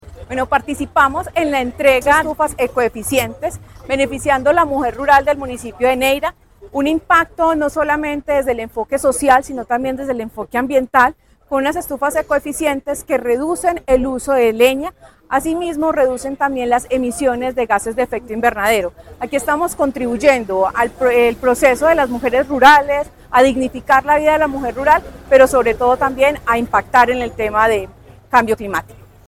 Paola Andrea Loaiza Cruz, secretaria de Medio Ambiente de Caldas.
Estufas-secretaria-Medio-Ambiente.mp3